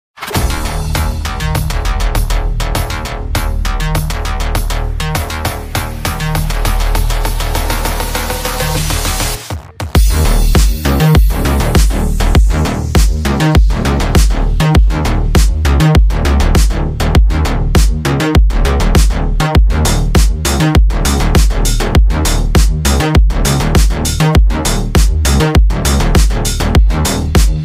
🔥🤖Global First! DEEPRobotics' Robot Dog Sound Effects Free Download